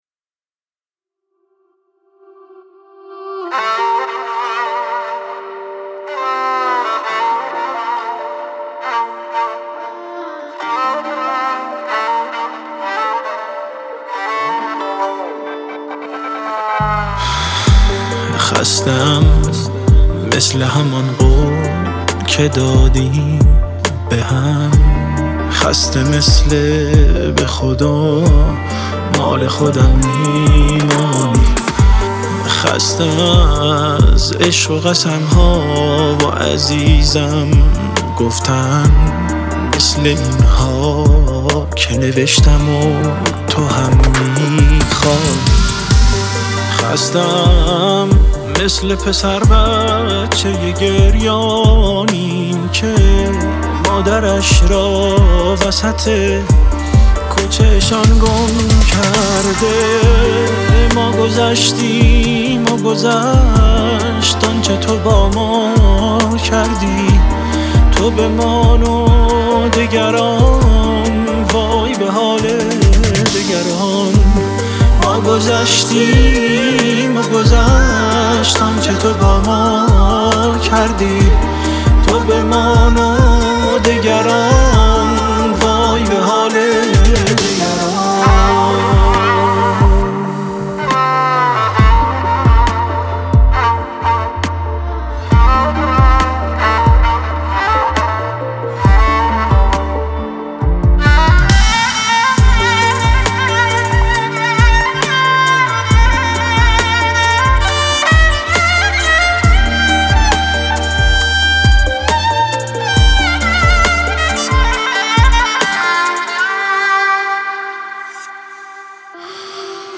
خواننده موسیقی ایرانی